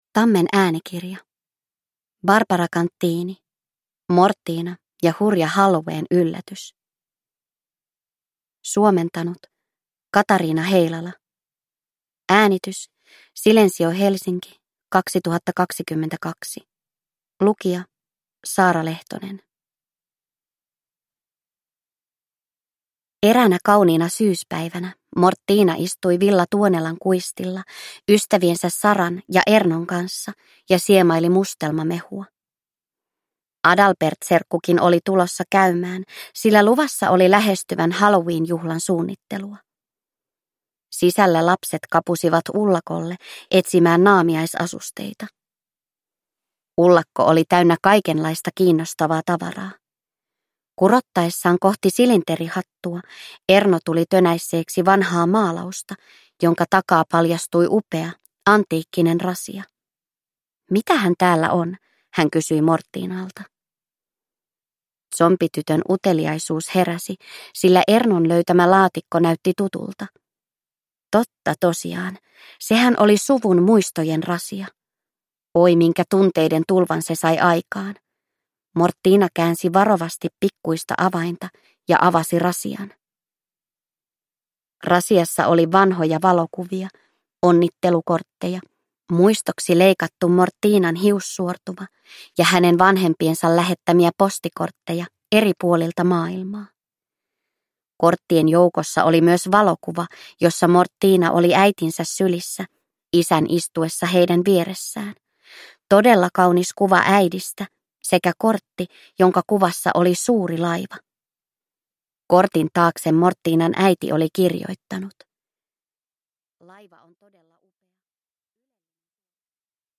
Mortina ja hurja halloween-yllätys – Ljudbok – Laddas ner